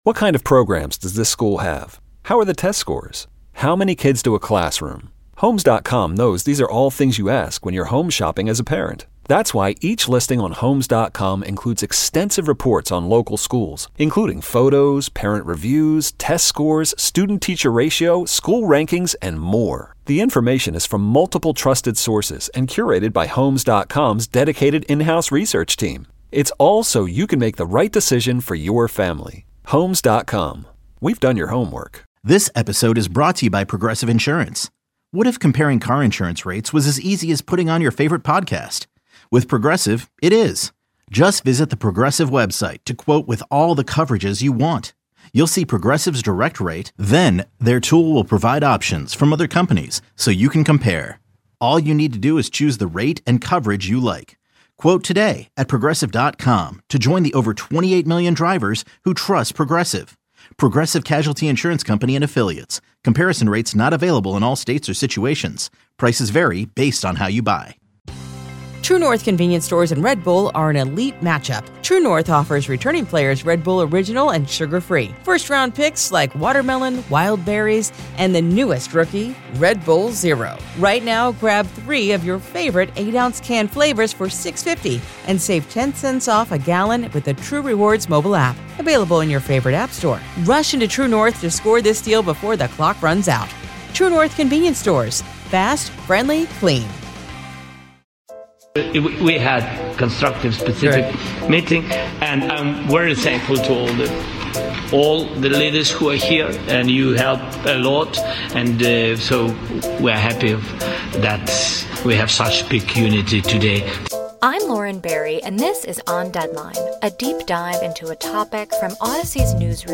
Catch up on the latest of what happened after the president of Ukraine visited the White House following President Donald Trump's peace talks with Russian president Vladimir Putin. Military experts and journalists weigh in on what to expect next as an analysis brings up a question: Did this all unfold because Barack Obama didn't intervene when Putin captured Crimea?